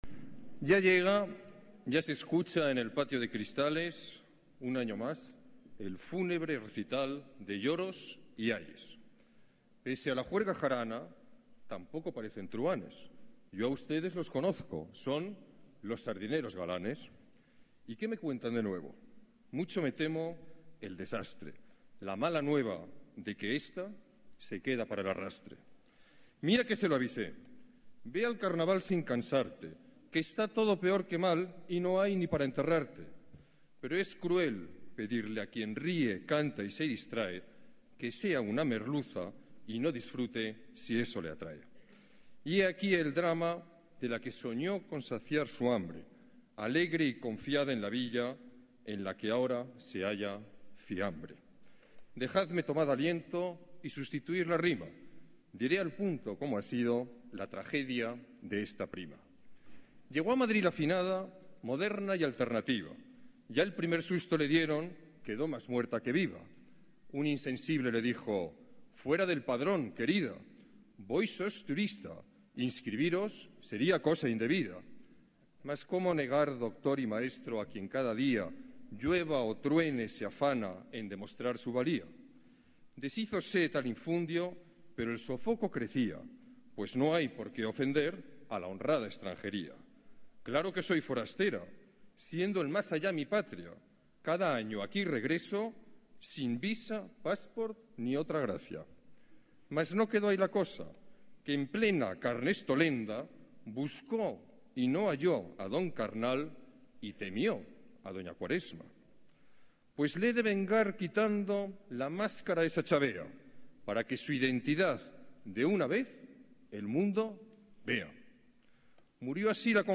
Nueva ventana:Palabras del alcalde en el Entierro de la Sardina